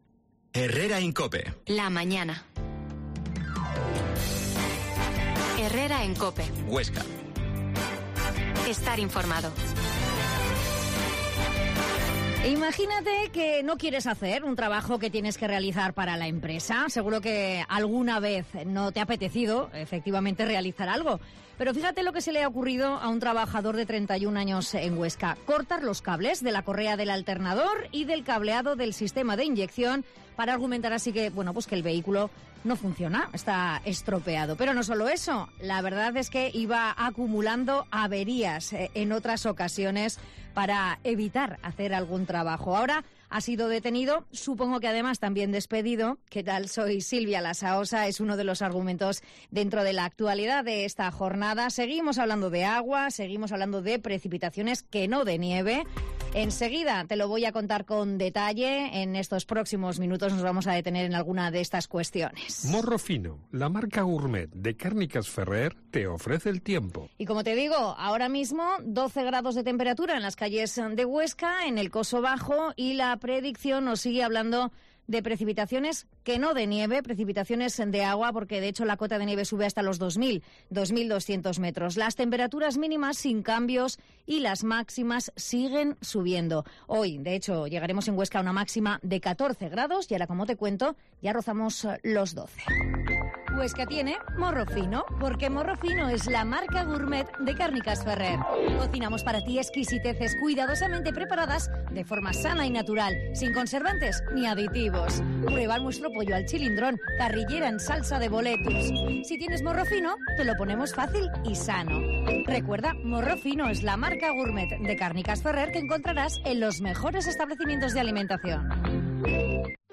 Herrera en COPE Huesca 12.50h Entrevista al alcalde de Almudevar, José Luis Abad